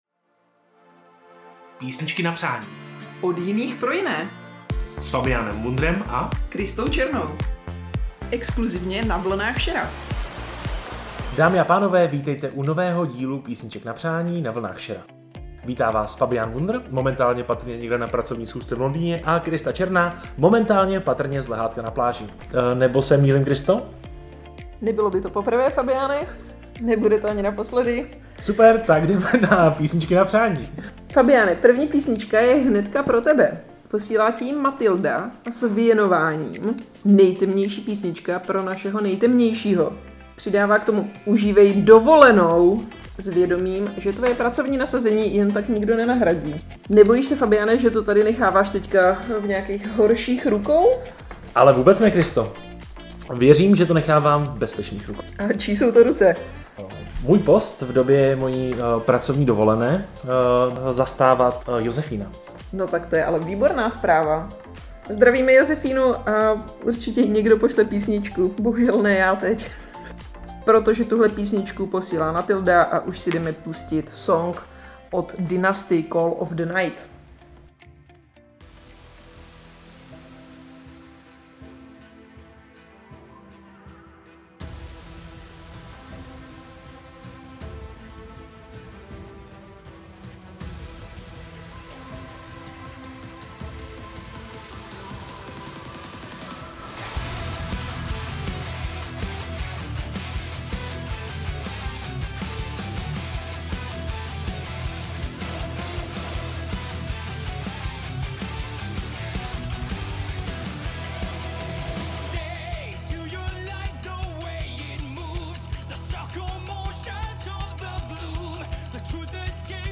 Písničky na Přání z daleka a blízka od přátel i rodiny